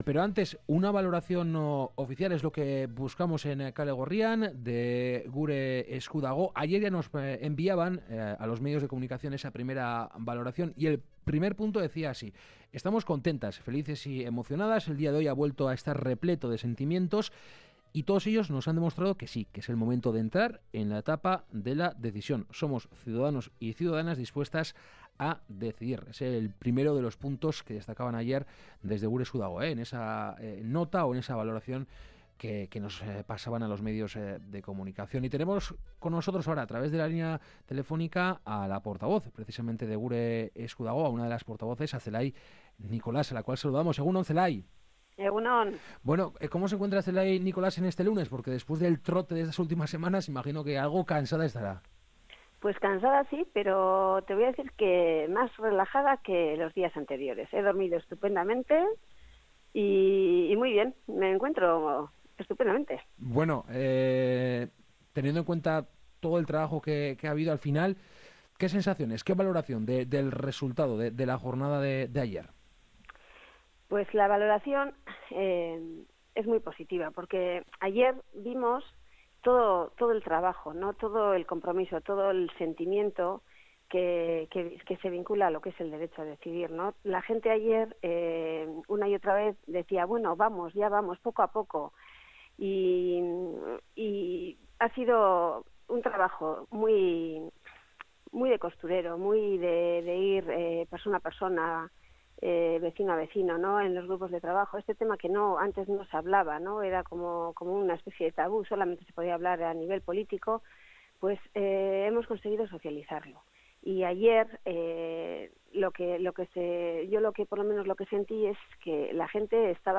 Esta mañana en Kalegorrian hemos entrevistado